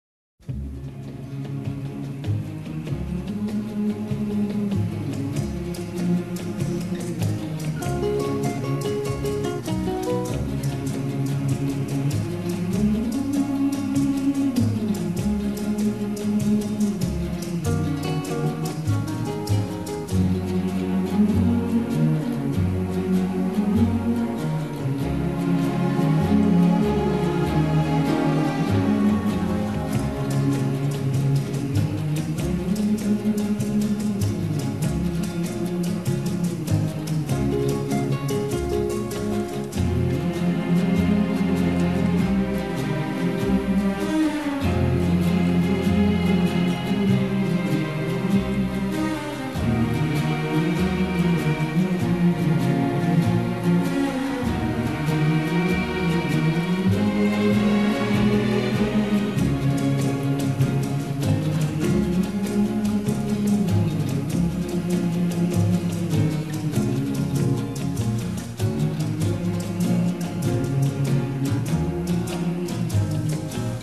Rutilante aleación de western crepuscular y comedia musical
con su singular voz penetrante y resquebrajada